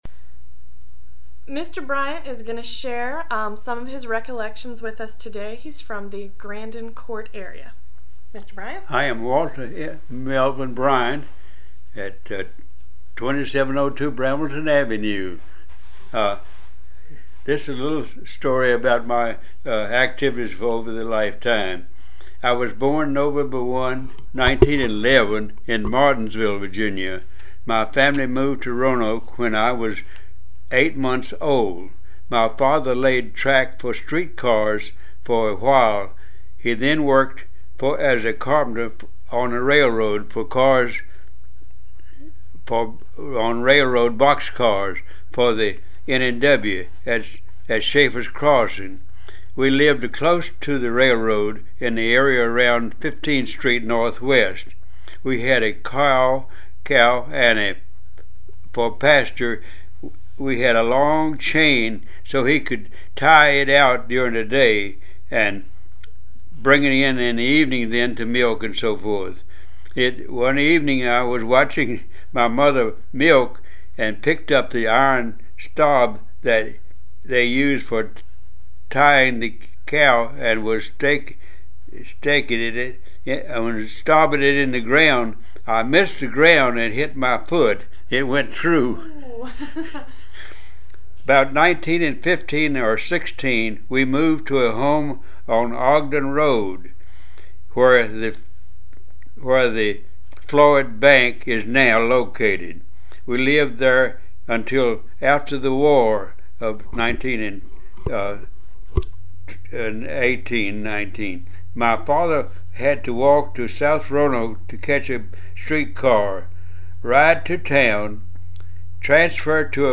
Neighborhood Oral History Project